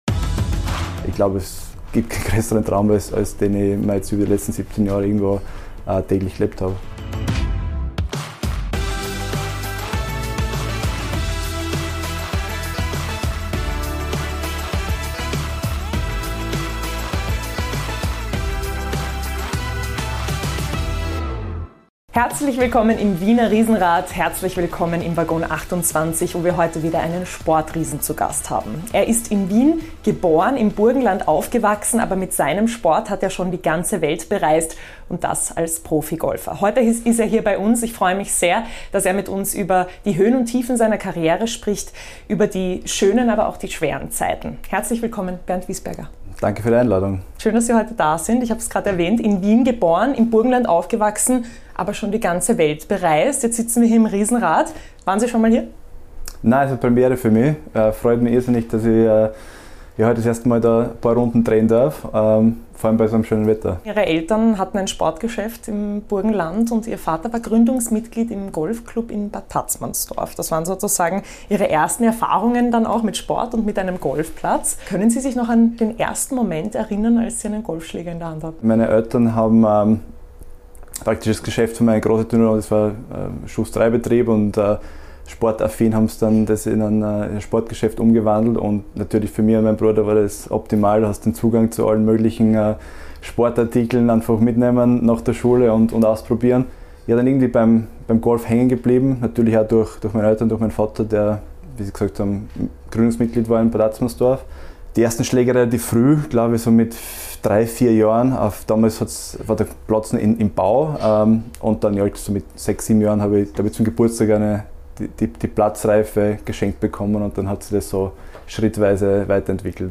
Im Sporttalk spricht der 37-jährige über seinen emotionalen Turniersieg 2012 in Österreich, die schwierige Zeit nach der Handgelenksverletzung 2018 und das darauffolgende "Bombenjahr" 2019, sowie über die Bedeutung des Golfsports in seinem Privatleben. Weiters verrät Wiesberger, welches Turnier alle Spieler gewinnen wollen, was das Besondere am Ryder Cup ist und was für ihn im Golf das Pendant zum 12. Mann im Fußball ist.